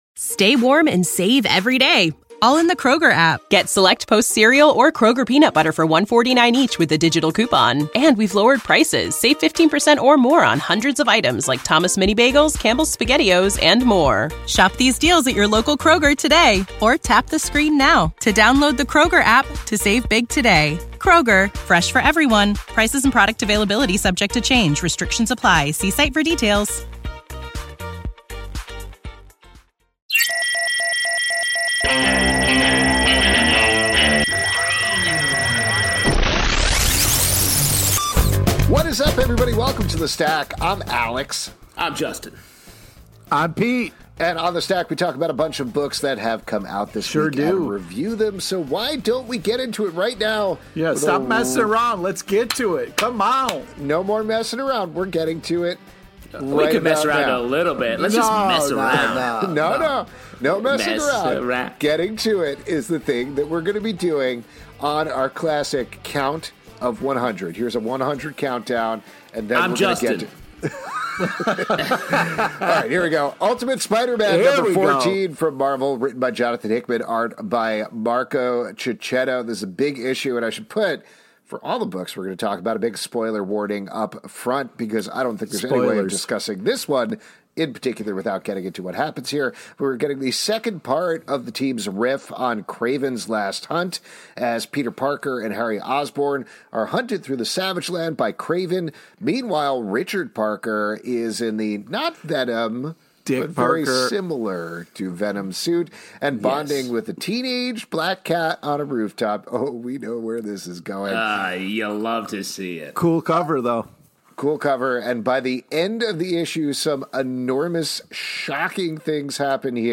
Comic Book Club is a LIVE weekly talk show about comic books, every Tuesday night at 7pm ET!